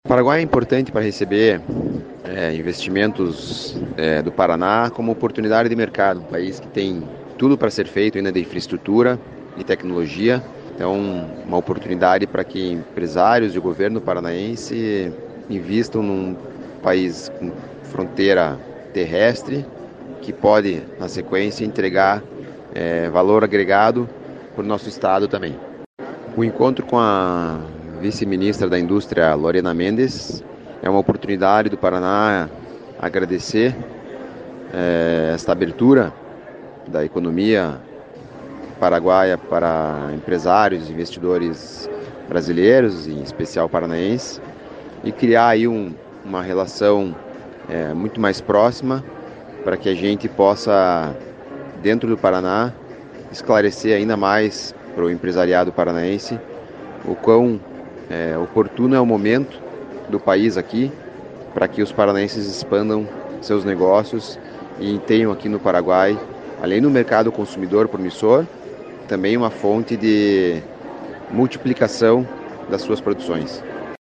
Sonora do diretor de Fomento da Secretaria da Indústria, Comércio e Serviços, Gustavo Wolff, sobre a Expo Paraguai-Brasil, maior feira de negócios entre os dois países